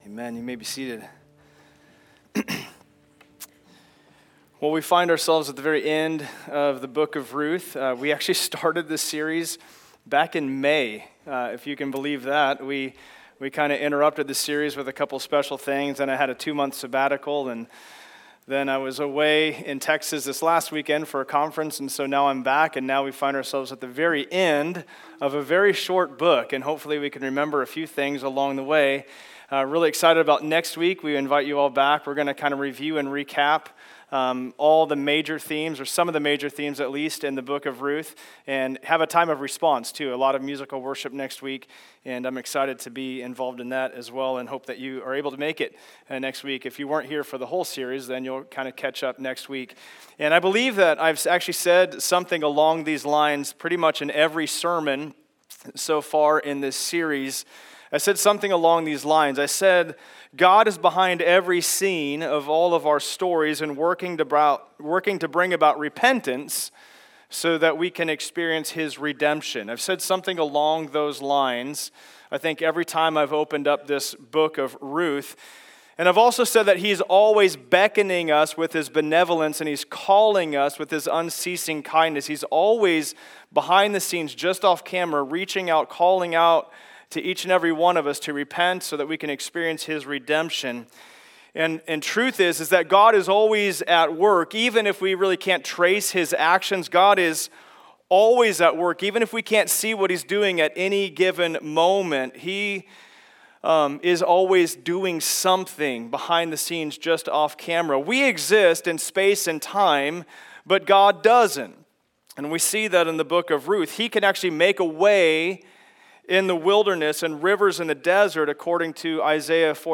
The Book of Ruth Passage: Ruth 4:13-22 Service Type: Sunday Service Download Files Notes « The Exposure of the Cross Ruth